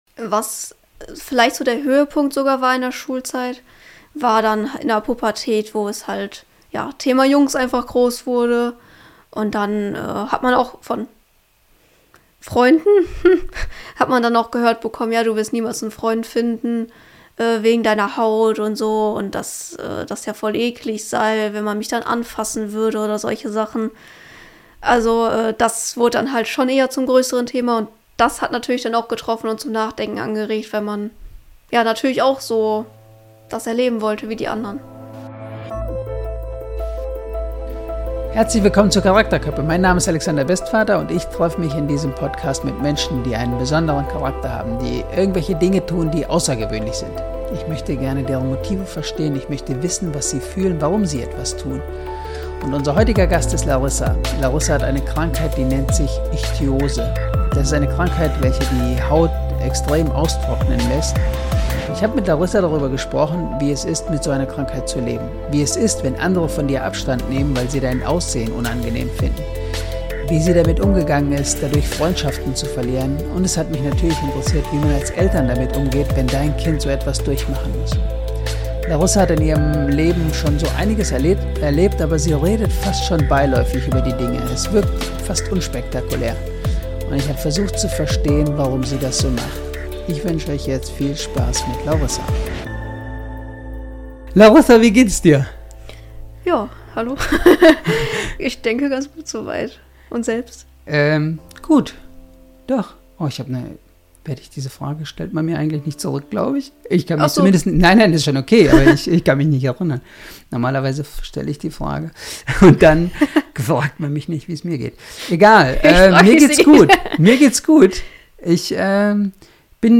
Ein ehrliches Gespräch über den Umgang mit Ablehnung.